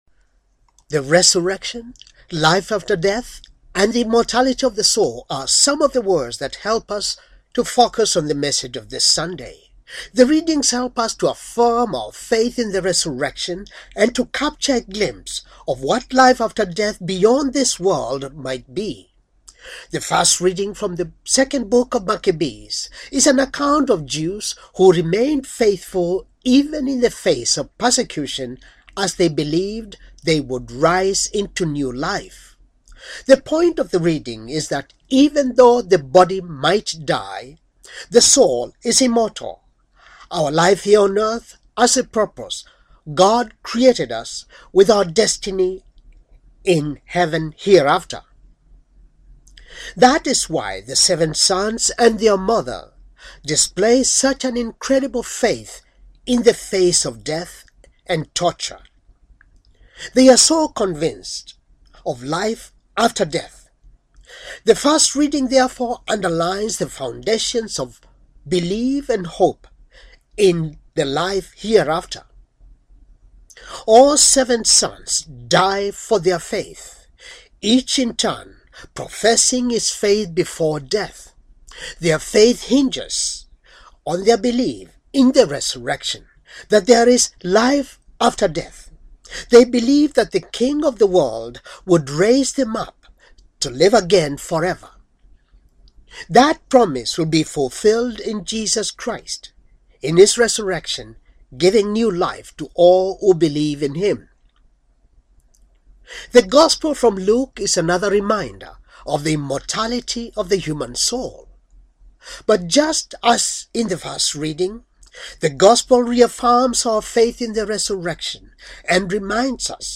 Homily, thirty, second, Sunday, ordinary, time, year c,